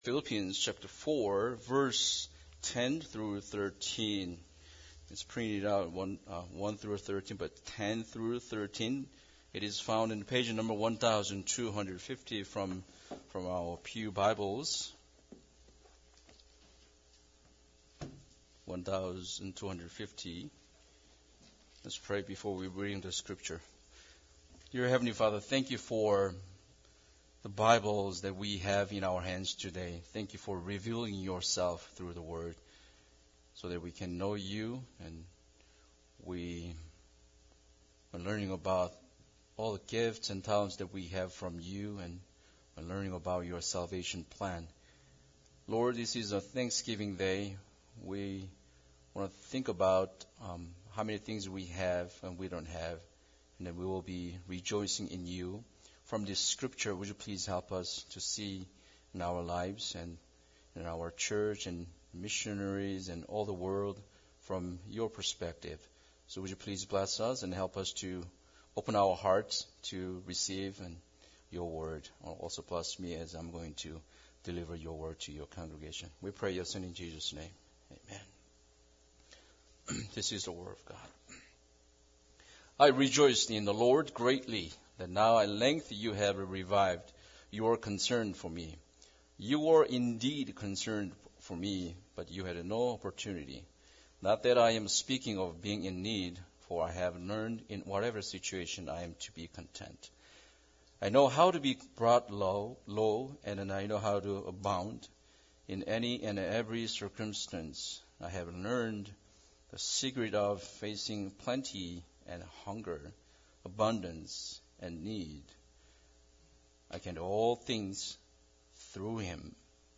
Philippians 4:1-13 Service Type: Special Service Bible Text